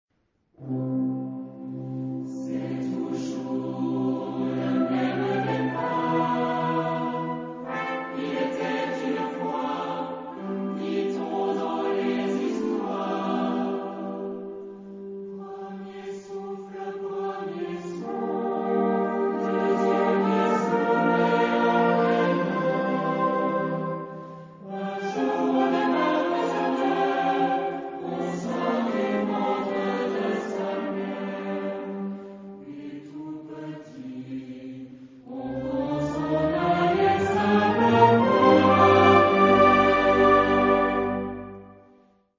Genre-Style-Forme : Profane ; Chanson ; Poème
Caractère de la pièce : émouvant
Type de choeur : SATB  (4 voix mixtes )
Instrumentation : Orchestre d'harmonie
Tonalité : do majeur